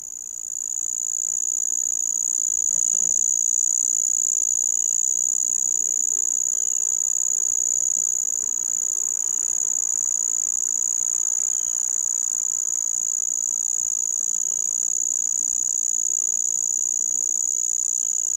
A singing male Handsome trig (Phyllopalpus pulchellus)
Around noon I noticed a cricket song that I had never heard around my house before. Armed with a directional microphone and a net I followed the twitter, and found him singing from the upper surface of a large leaf, about 6 feet above the ground.